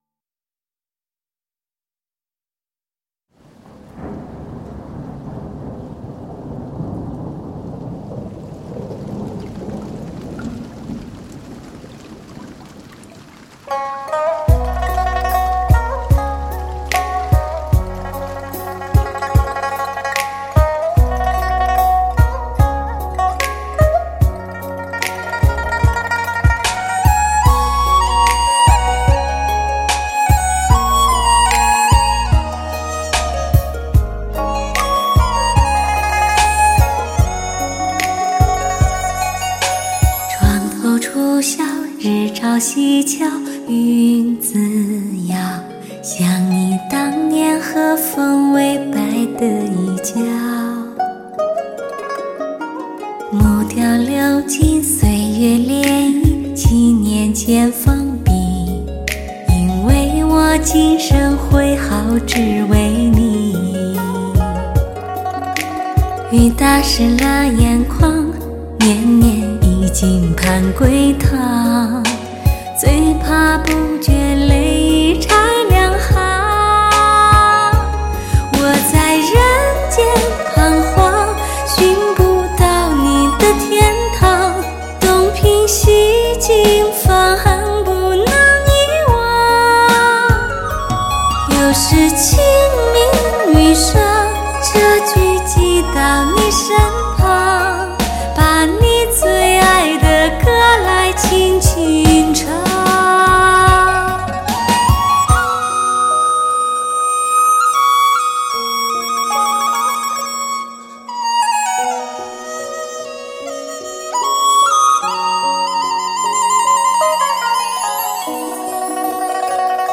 人声与打击乐的对话
HI-END试音新定义、新标准，再造音质神话，既保留了黑胶LP唱盘的高保真与自然感，
又具备了CD的高清晰与低噪音的优点。